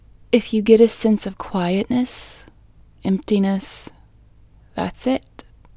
LOCATE IN English Female 13